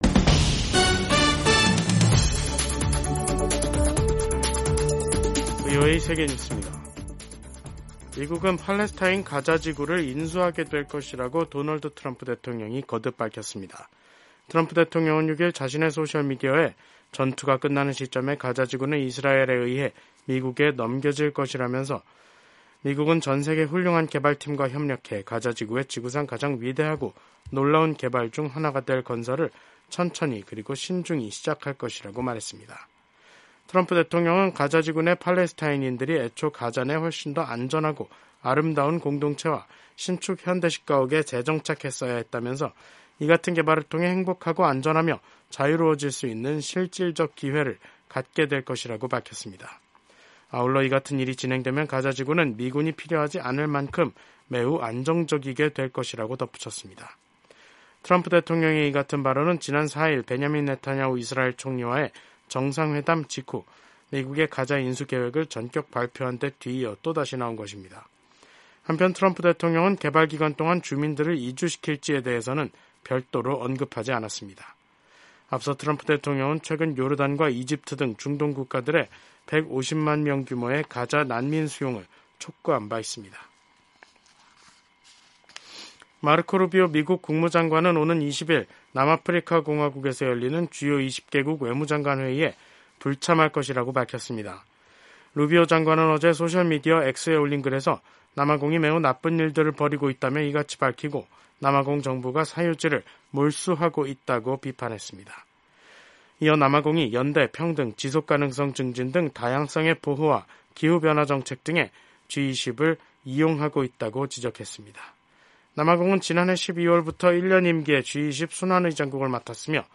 생방송 여기는 워싱턴입니다 저녁